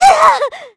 Hilda-Vox_Damage_kr_06.wav